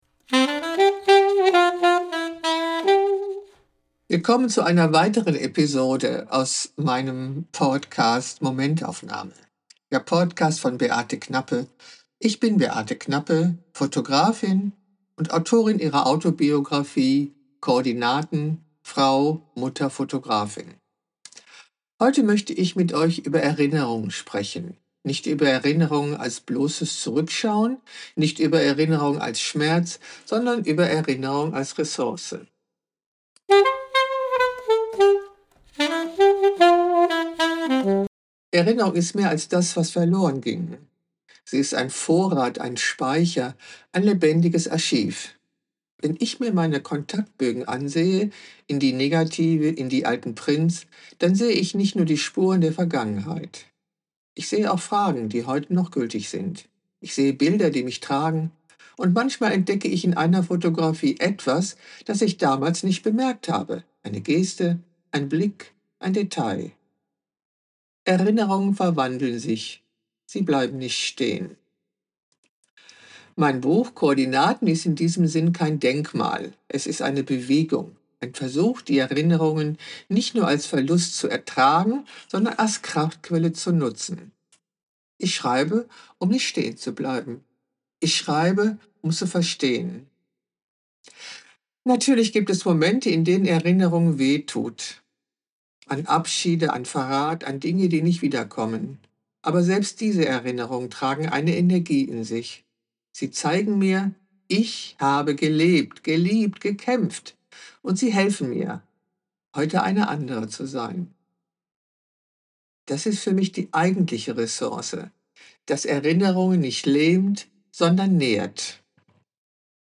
Weil ein Buch nicht nur gelesen, sondern auch gehört werden will.
Meine Stimme trägt den Rhythmus meiner Sprache, die Atempausen
und Zwischentöne.